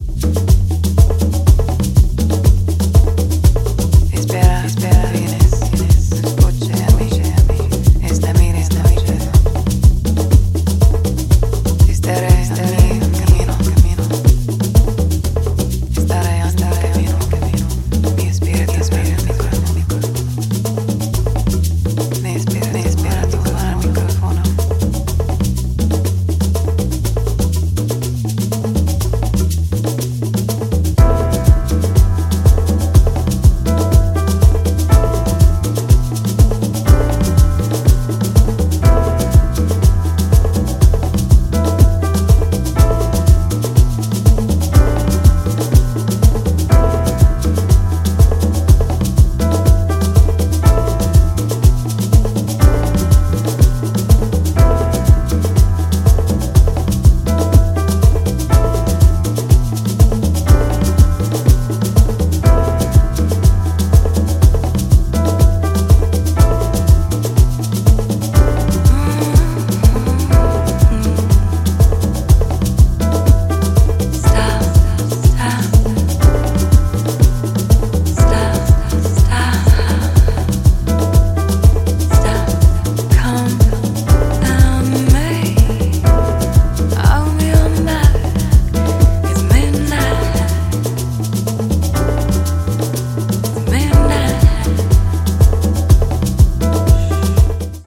Strong spiritual house vibes
ethereal vocals
emotive percussion and lush atmospheres
Deep house
Soulful house